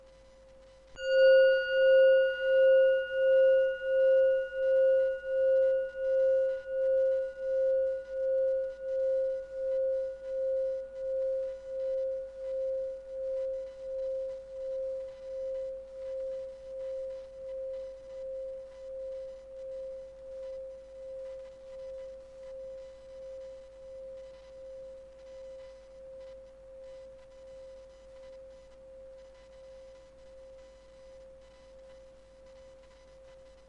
Solfeggio 能量棒风铃 " Solfeggio 风铃 Mi 528 Hz 单切割
描述：这是我的solfeggio 6风铃的单音，Mi 528 Hz的声音用大胆软件切割和改进;
标签： 风力 视唱练耳 528赫兹 大胆 单切 钟声
声道立体声